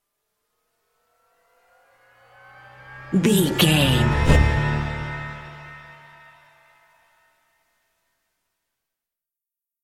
Aeolian/Minor
D
synthesiser
percussion
ominous
dark
suspense
haunting
tense
creepy
spooky